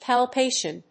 音節pal・pa・tion 発音記号・読み方
/pælpéɪʃən(米国英語)/